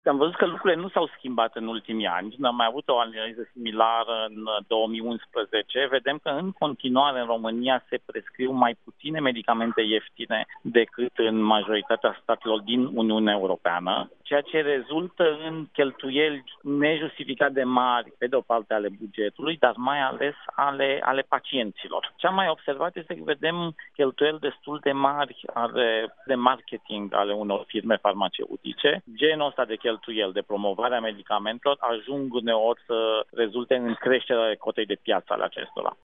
Contactat de Europa FM, Bogdan Chirițoiu a spus că o posibilă explicație ar putea fi bugetul mare alocat de firmele din industria farmaceutică pentru promovarea medicamentelor.